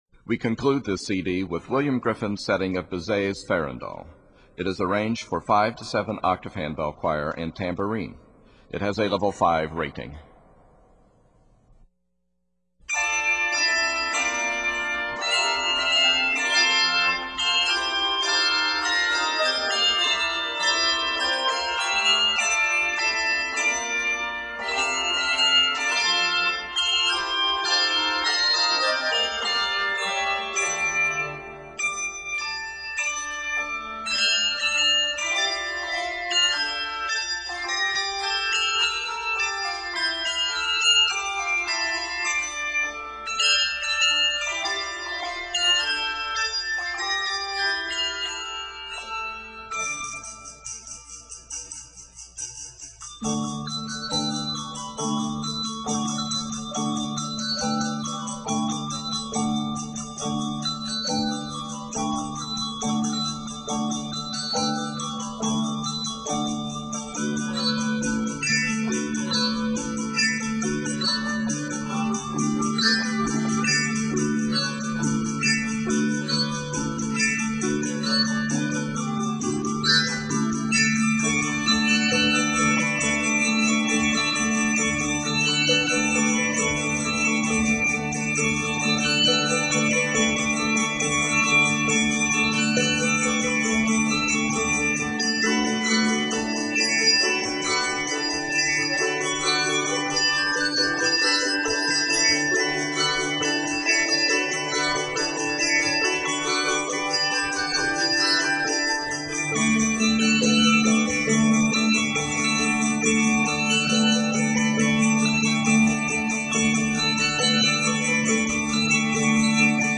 advanced handbell choir
Octaves: 5-7